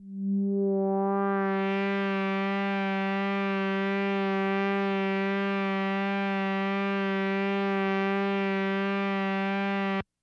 标签： FSharp4 MIDI音符-67 罗兰-SH-2 合成器 单票据 多重采样
声道立体声